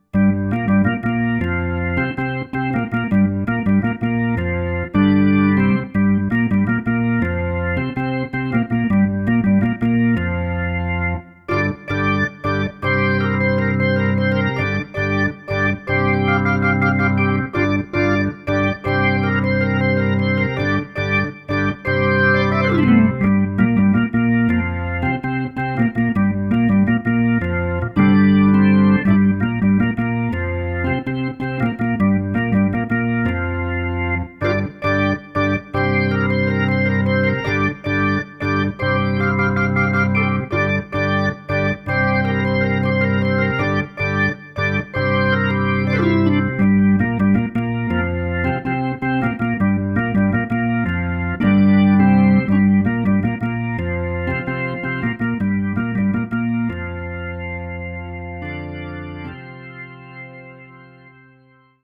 Solopart A. Hammond Latin Rock.